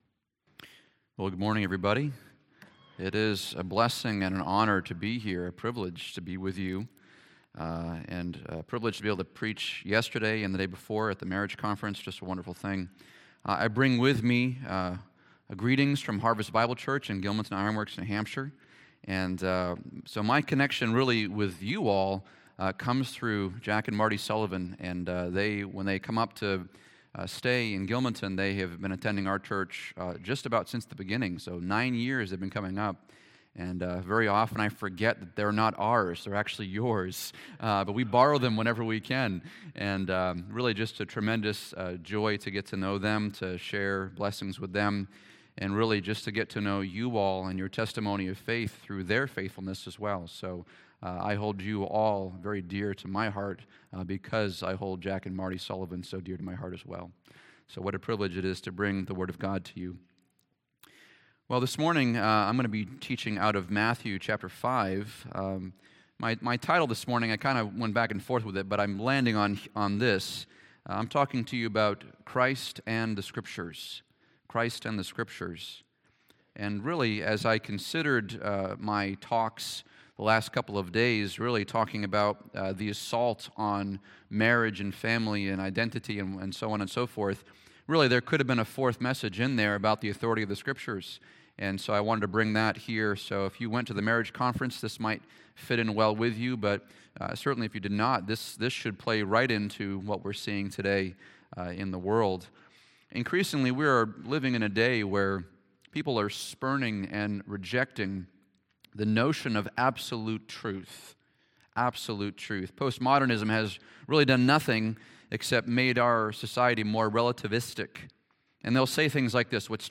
A message from the series "2022 Sermons."